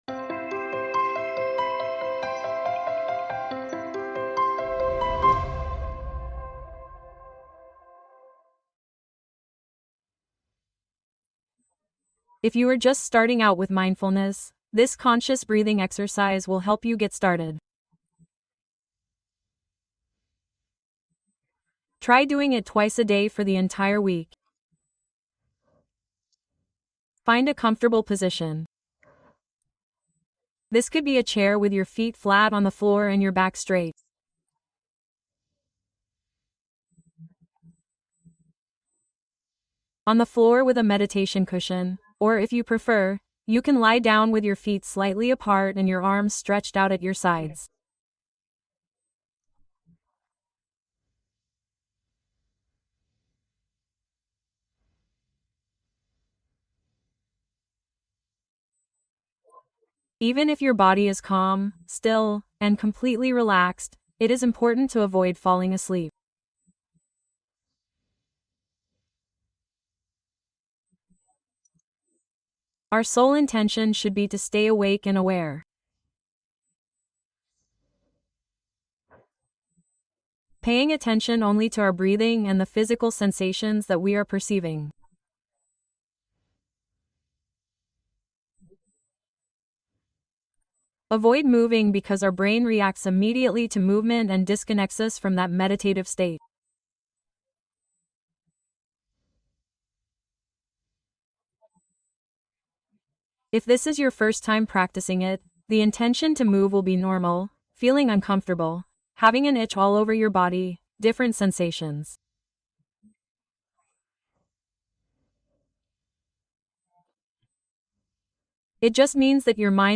Meditación mindfulness para principiantes: respiración guiada para la calma